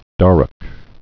(därk)